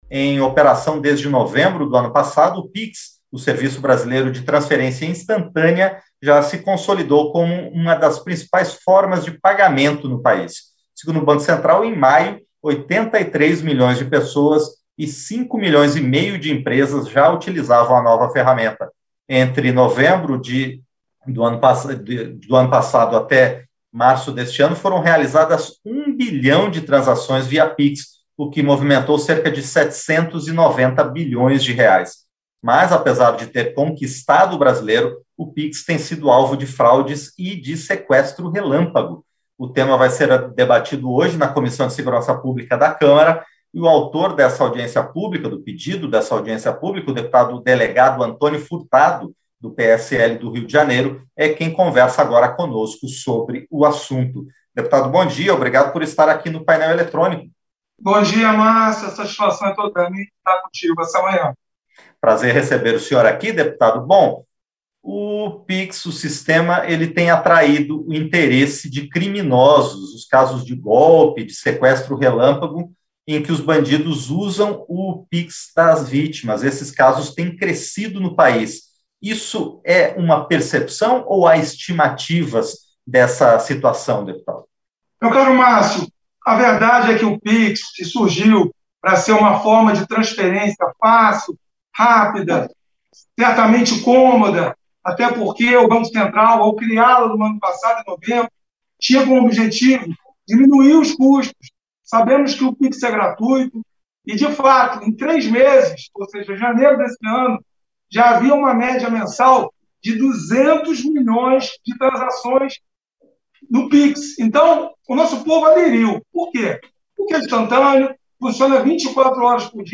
Entrevista - dep. Delegado Antônio Furtado (PSL-RJ)